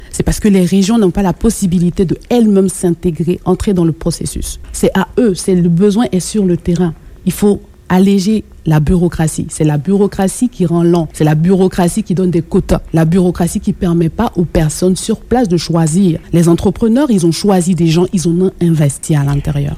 En entrevue à Radio Beauce, elle a souligné l’importance économique de la région, qu’elle décrit comme un véritable poumon pour le Québec.